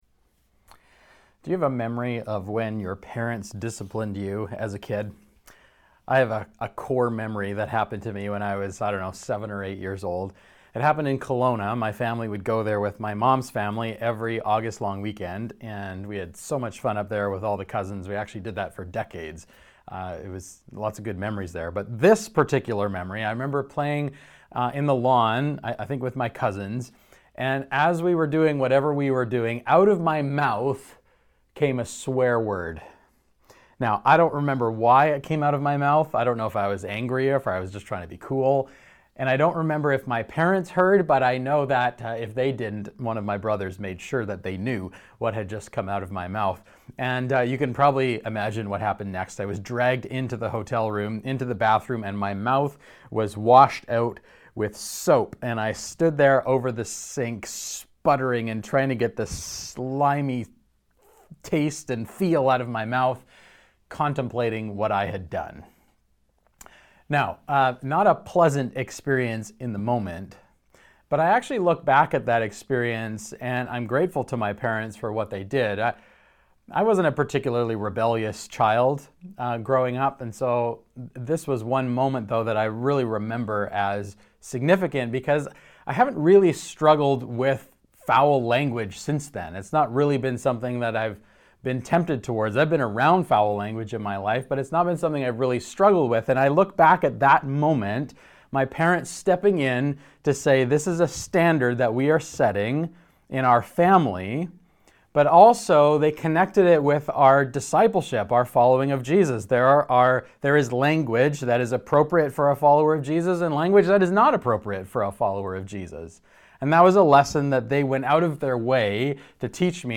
SERMON NOTES God disciplines those He loves for their good Children are disciplined We should submit to God’s discipline for our own good Disciplined living brings reward Pray about it: What difficult circumstance are you experiencing in life right now?